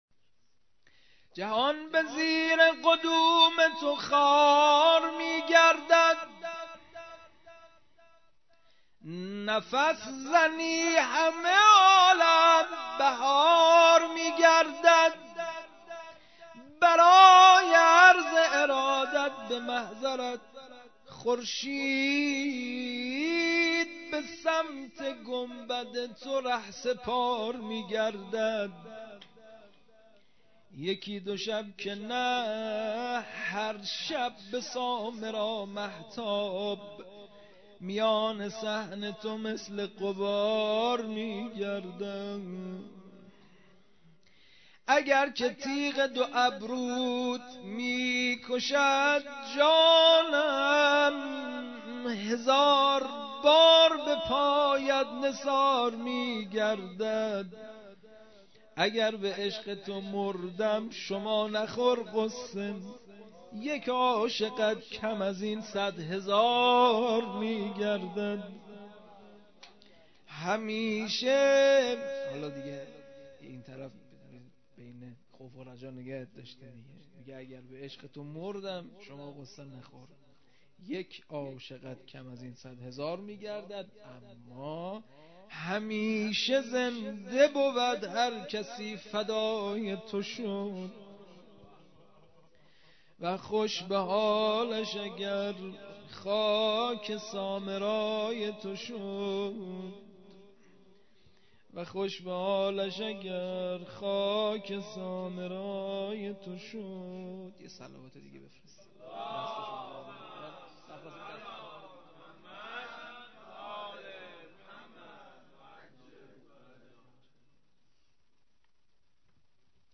مراسم هفتگی؛ جشن میلاد امام علی النقی حضرت هادی علیه السلام؛ قسمت سوم